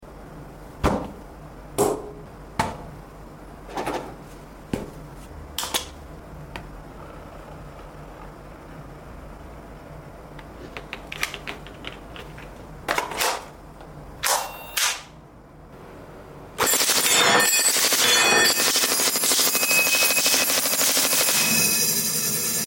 F4 Gel Blaster: Full Auto sound effects free download